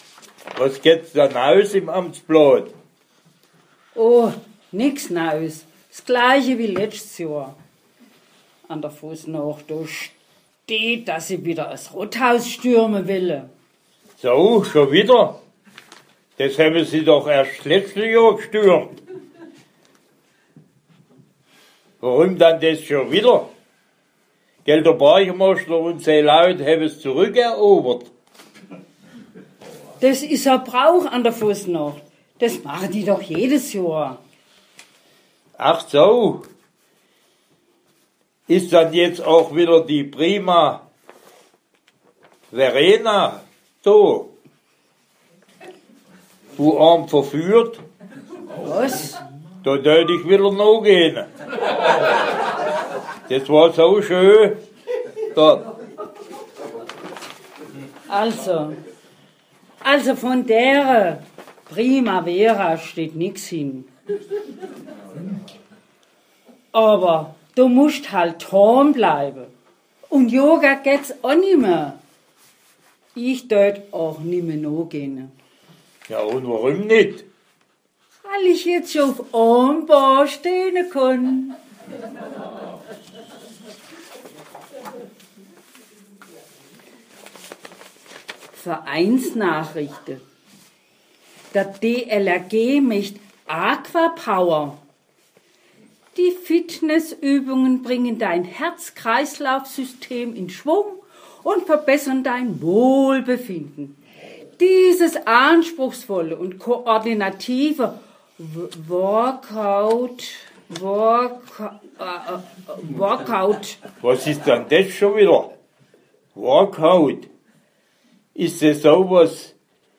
08.Februar Fasching
Der Vorspann zum Sketsch wurde versehentlich nicht aufgezeichnet
2018 Sketsch.mp3